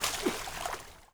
SPLASH_Subtle_02_mono.wav